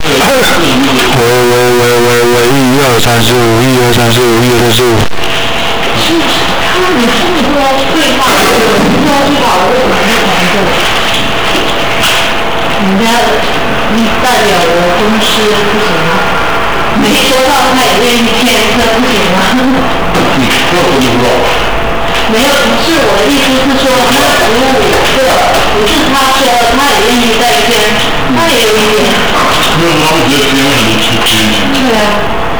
关于TLV320AIC3104录音噪声过大的咨询
使用的是MIC1RP/MIC1RM通道，以下是开始录音时所有寄存器的值：